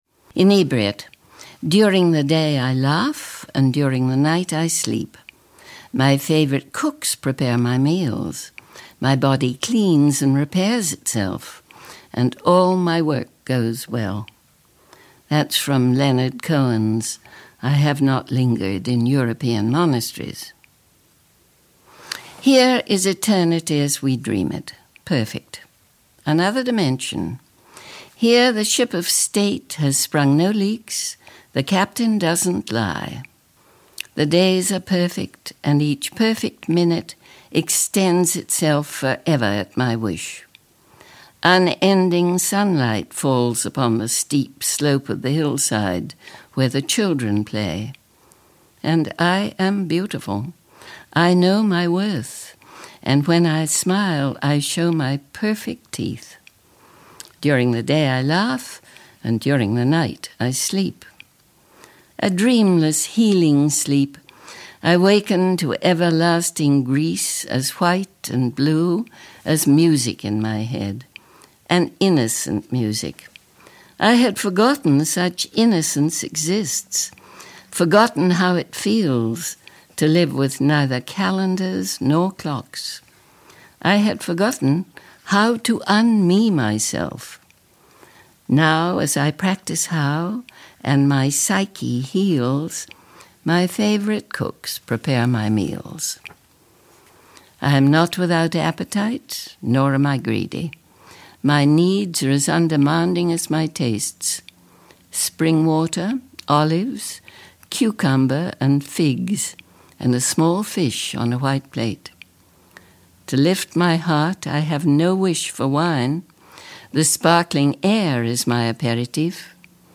P.K. Page reads Inebriate from Hologram: A Book of Glosas
This poem is from The Filled Pen: an Outlaw Editions Audio book. Poems by P.K. Page Written and narrated by P.K. Page.